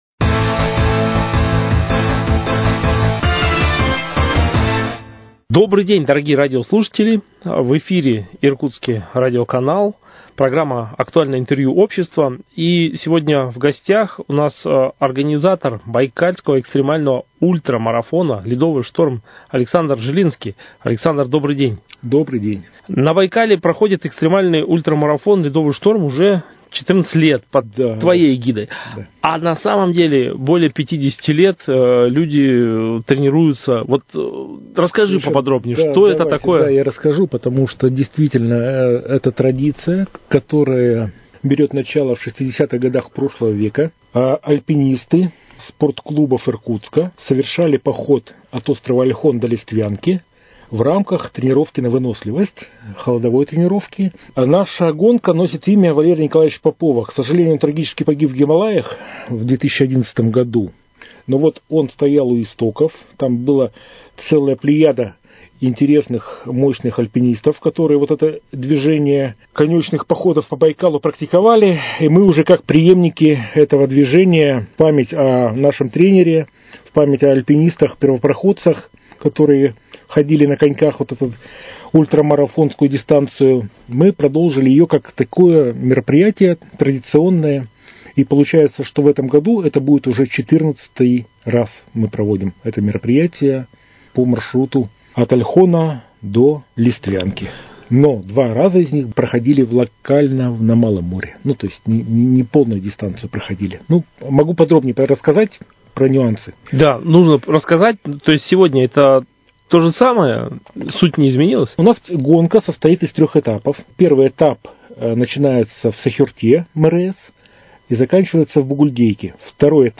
Актуальное интервью: О проведении Байкальского экстремального ультрамарафона «Ледовый шторм»